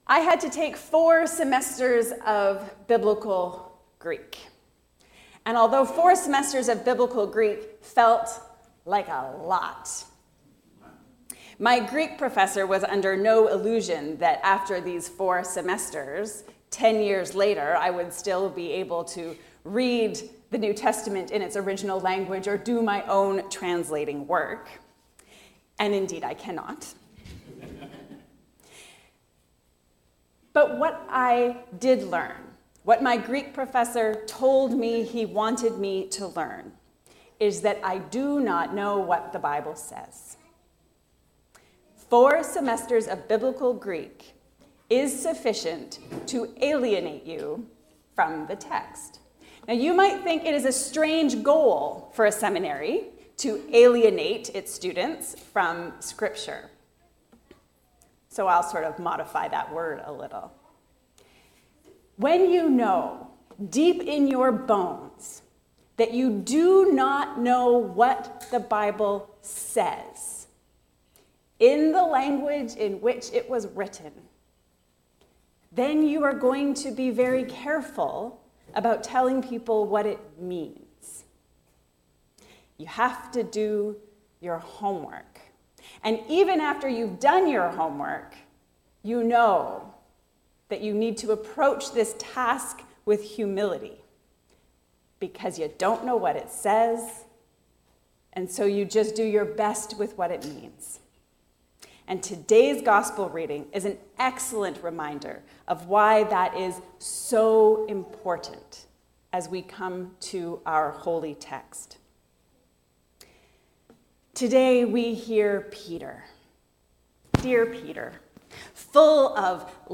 Holding the keys to the kingdom. A sermon on Matthew 16:13-20